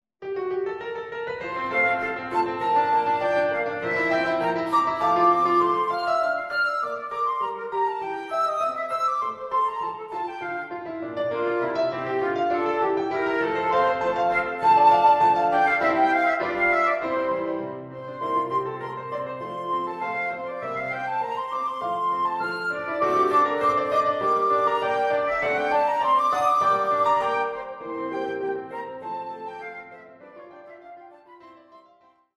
Flute and Piano